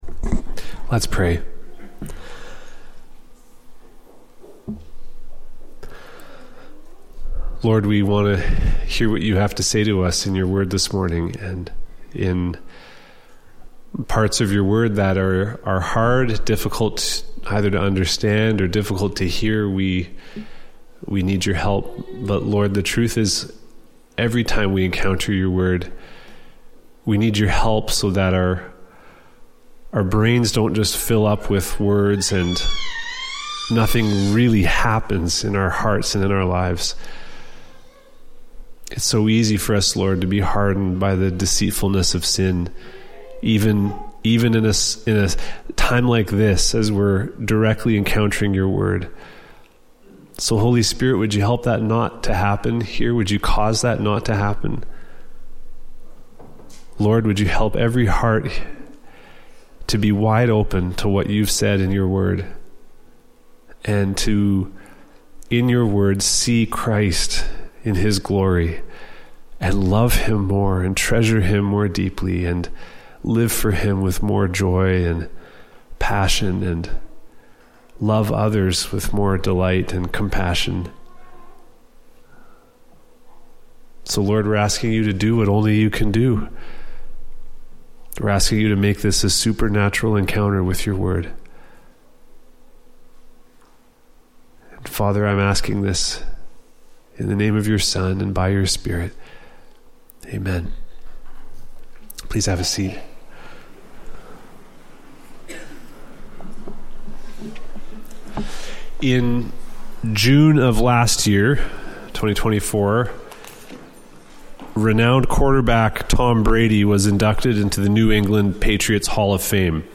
Emmanuel Baptist Church of Nipawin Sermons